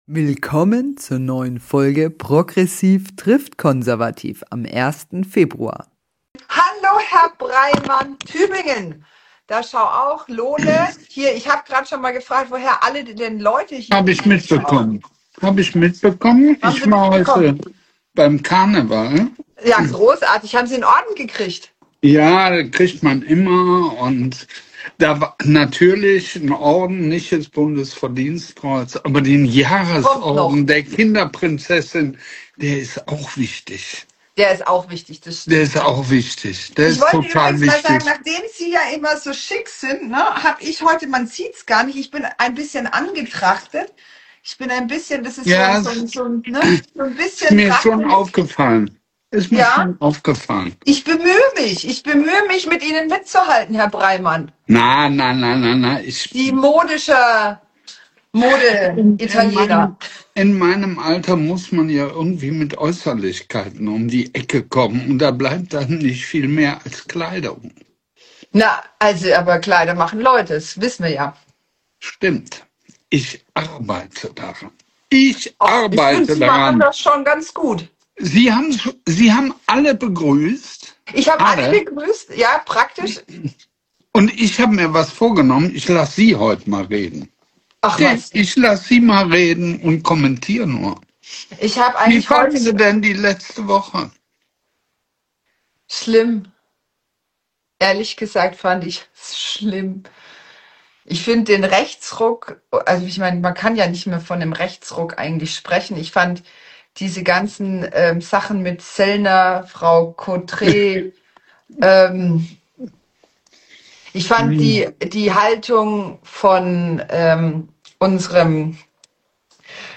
zwei Perspektiven, ein Gespräch